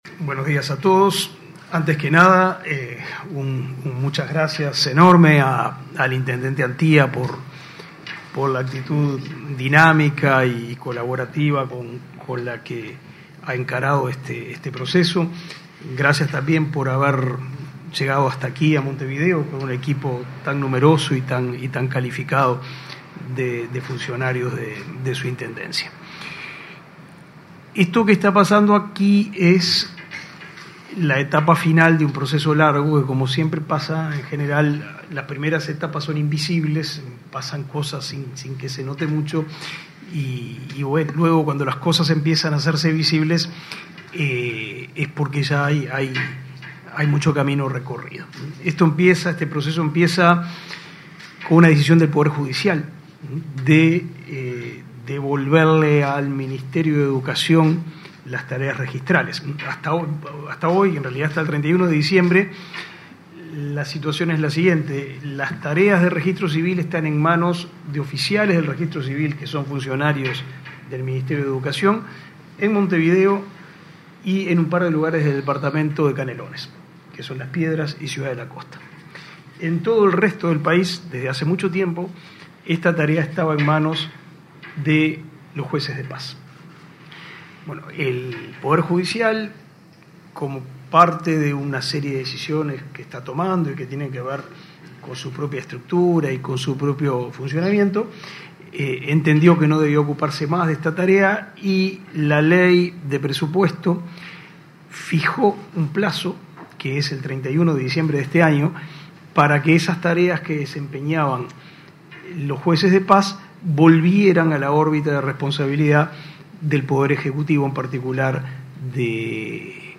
Conferencia de prensa por acuerdo entre el MEC e Intendencia de Maldonado
Conferencia de prensa por acuerdo entre el MEC e Intendencia de Maldonado 03/11/2021 Compartir Facebook X Copiar enlace WhatsApp LinkedIn El Ministerio de Educación y Cultura (MEC) y la Intendencia de Maldonado acordaron, este 3 de noviembre, que las funciones del Registro de Estado Civil pasarán a ser potestad de la comuna fernandina. Participaron en el acto, el ministro Pablo da Silveira, y el intendente Enrique Antía.